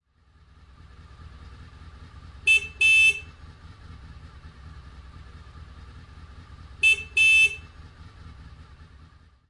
10 喇叭声